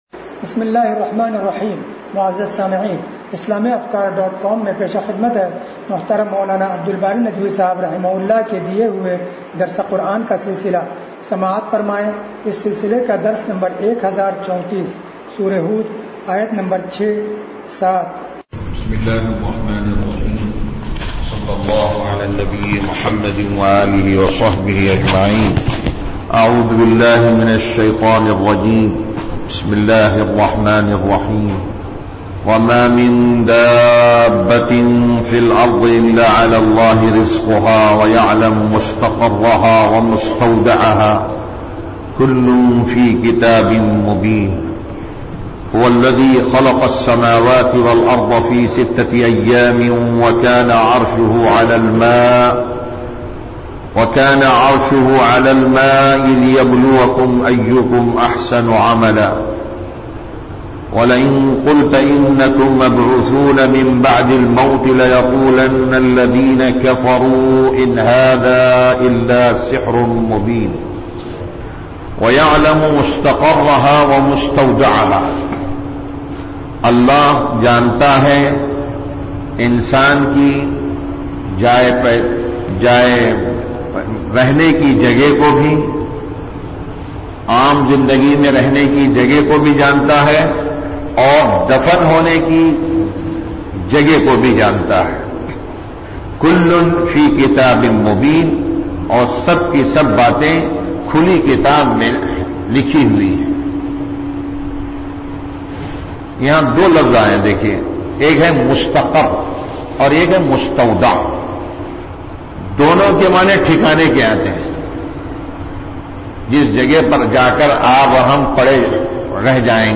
درس قرآن نمبر 1034